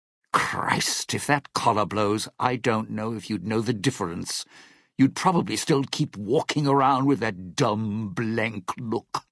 Category: Dead Money audio dialogues Du kannst diese Datei nicht überschreiben. Dateiverwendung Die folgende Seite verwendet diese Datei: Dean Domino Metadaten Diese Datei enthält weitere Informationen, die in der Regel von der Digitalkamera oder dem verwendeten Scanner stammen.